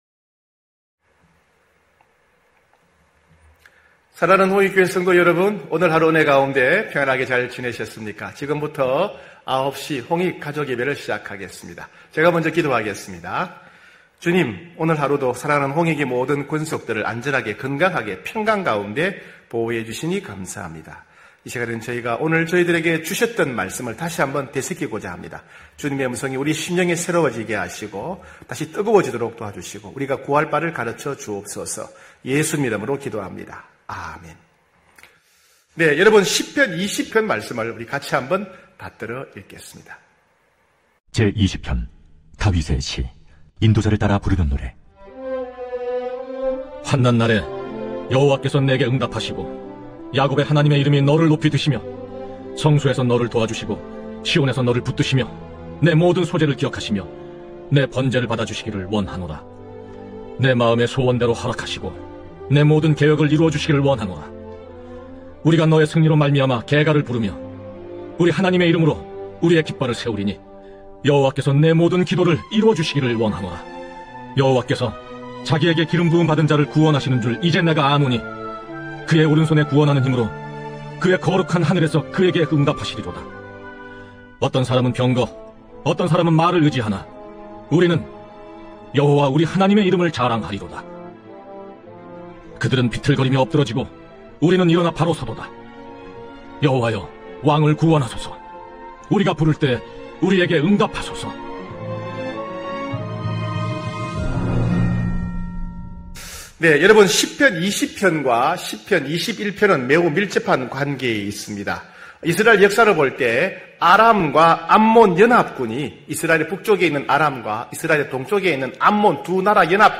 9시홍익가족예배(5월22일).mp3